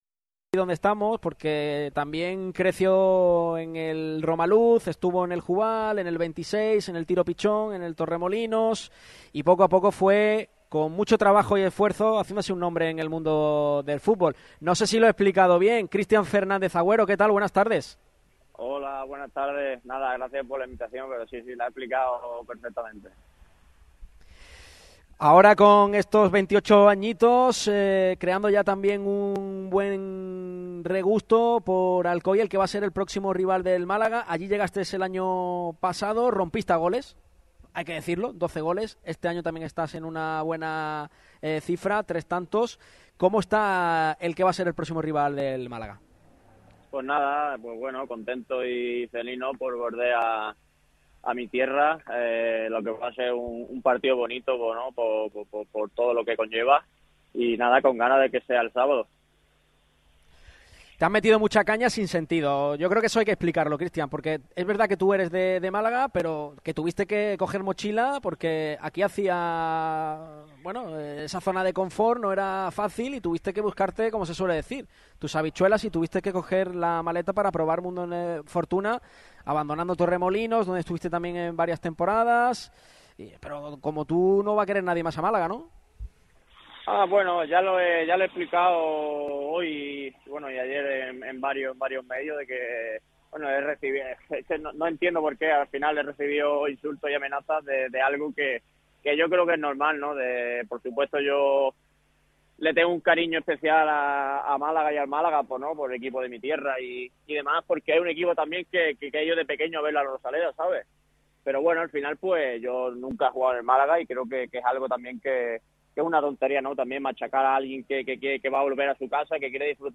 Este jueves pasó por Radio MARCA Málaga un protagonista que pisará de forma distinta La Rosaleda.